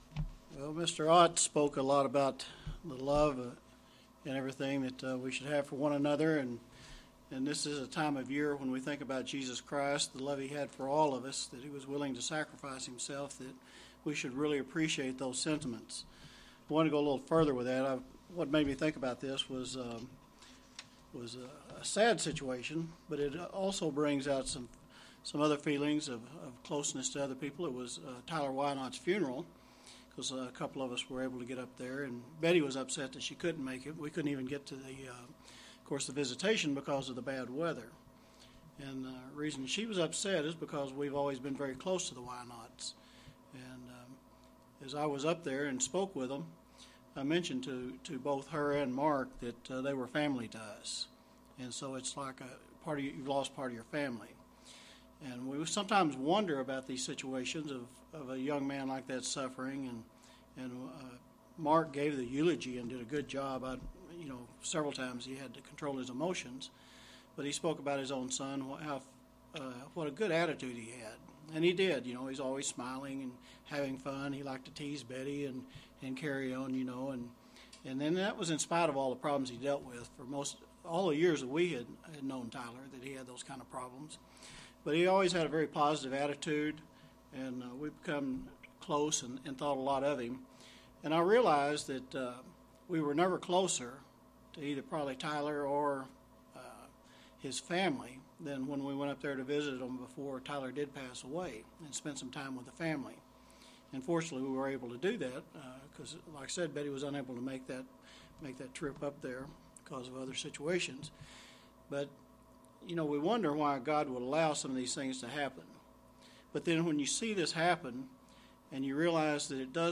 UCG Sermon Studying the bible?
Given in Springfield, MO